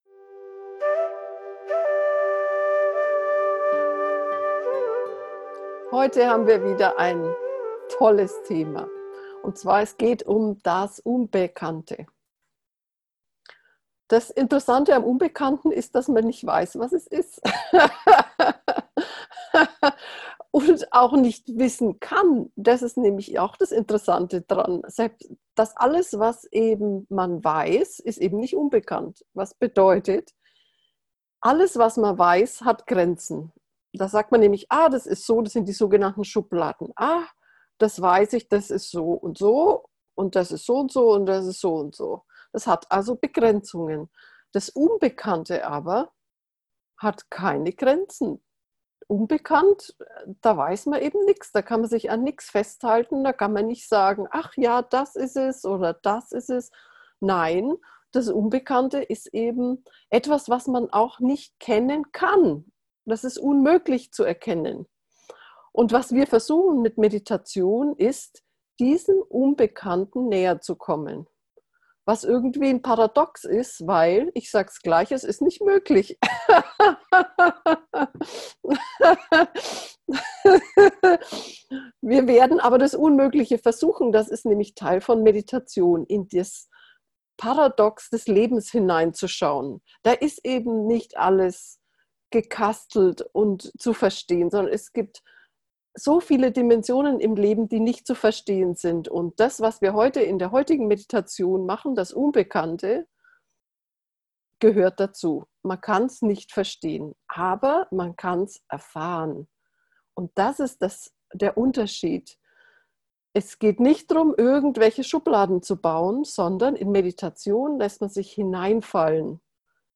Geführte Meditationen
unbekannte-begrenzungen-aufloesen-gefuehrte-meditation